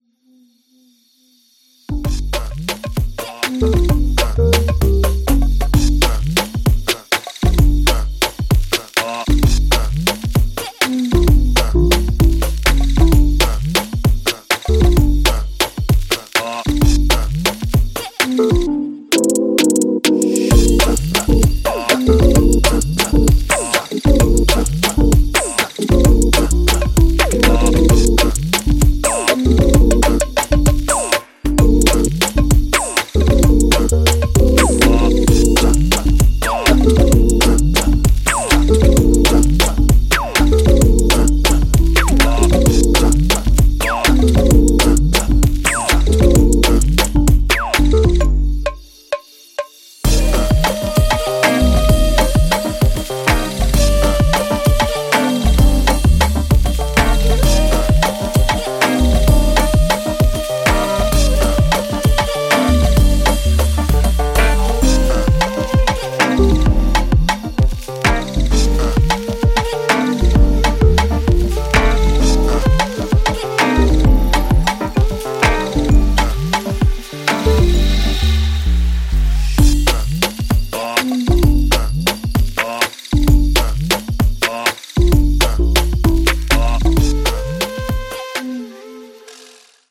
ジャンル(スタイル) DEEP HOUSE / BROKEN BEAT